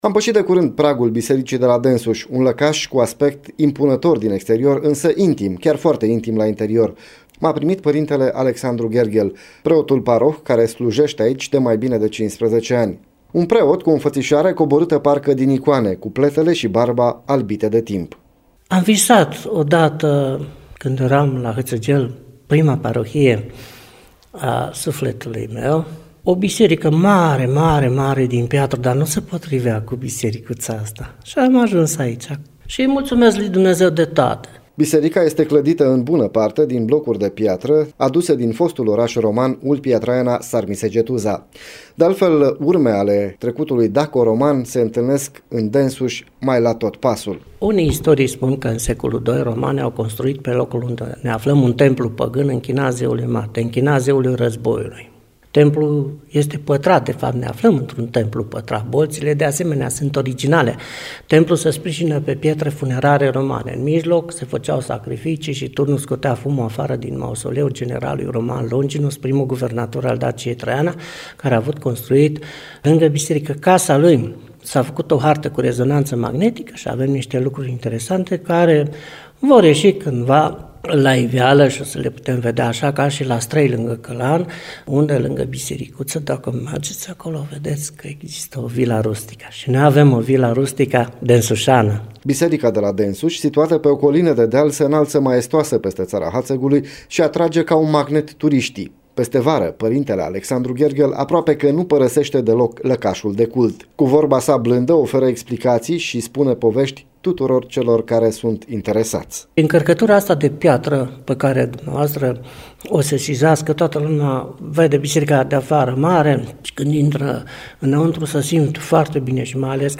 reportajul în format audio